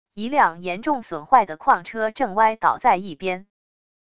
一辆严重损坏的矿车正歪倒在一边wav音频